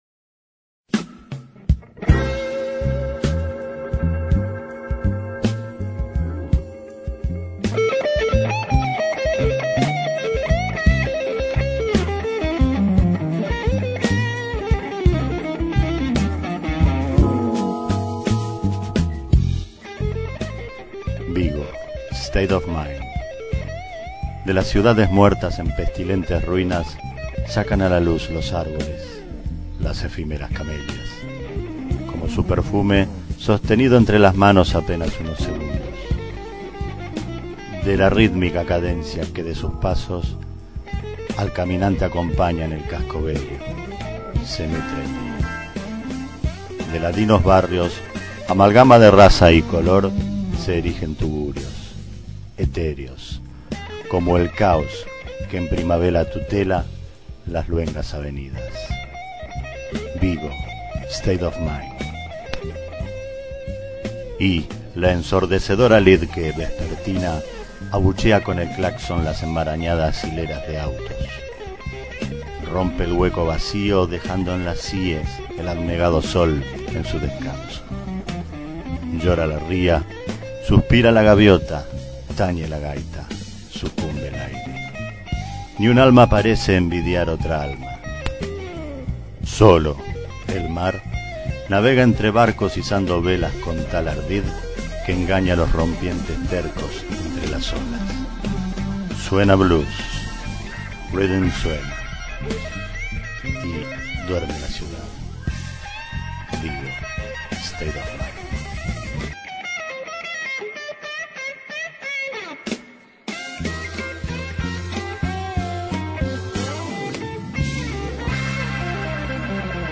Inicio Multimedia Audiopoemas Vigo state of mind.